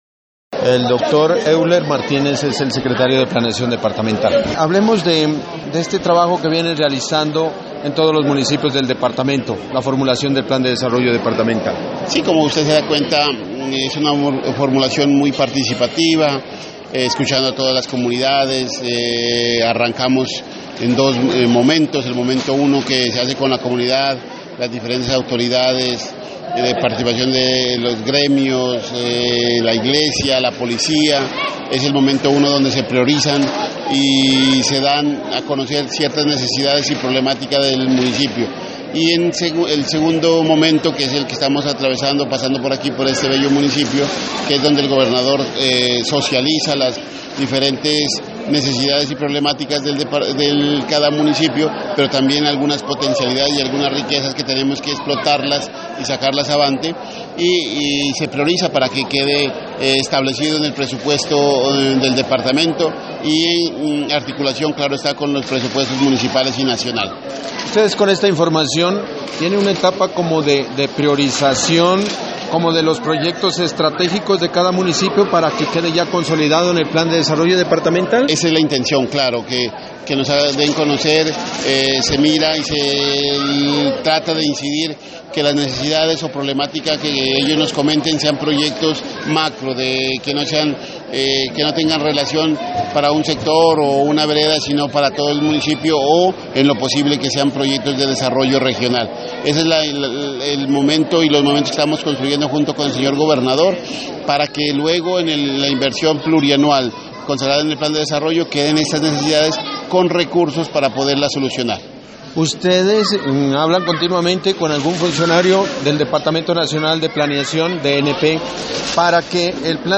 Entrevista con el secretario de planeación Euler Martínez: